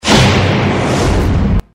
Message Sujet du message: Identification de sons
Je cherche à identifier les bruitages qui ont été utilisés au début de la B.A de M6 intitulée "M6 émotions".
Quelqu'un pourrait-il identifier les deux premiers bruitages utilisés?